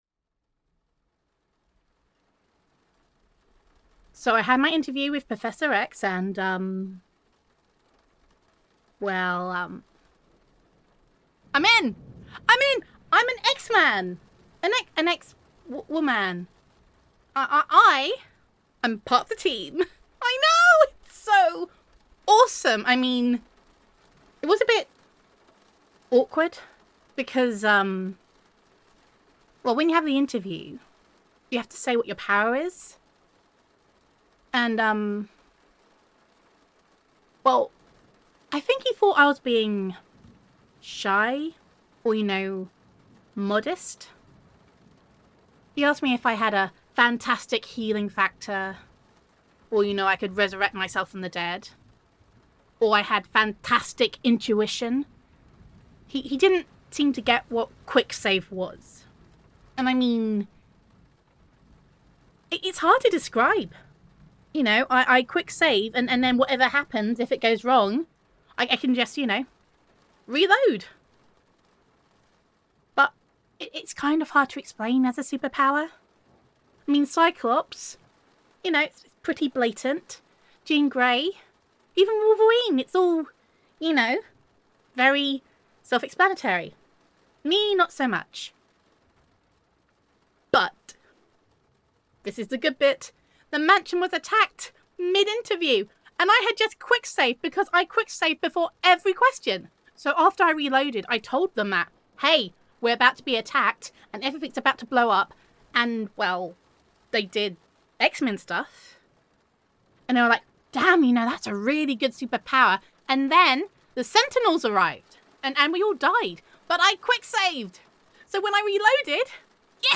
[F4A] What Is the Singular of X-Men? [Girlfriend Roleplay][Mutant Roleplay][Quick Save][the Difficulty of Choosing a Mutant Name][Gender Neutral][Your Girlfriend Tells You How She Passed Her Interview With Professor X]